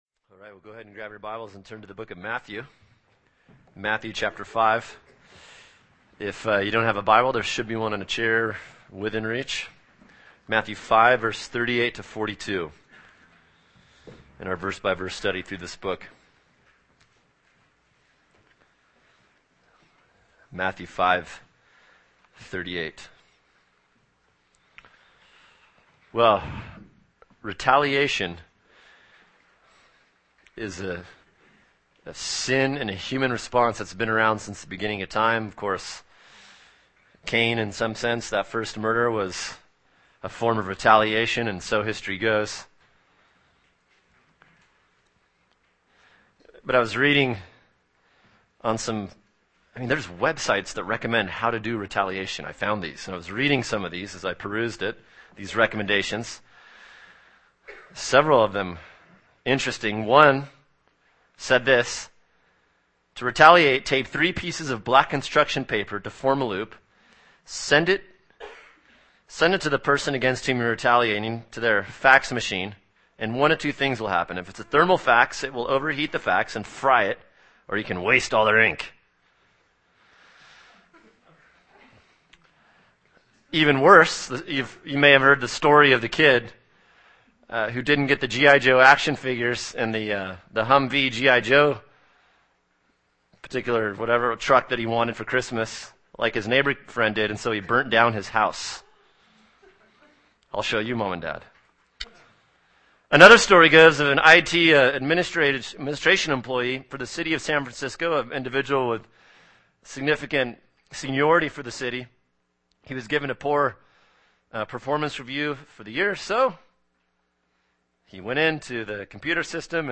[sermon] Matthew 5:38-42 “Grace for Retaliators” | Cornerstone Church - Jackson Hole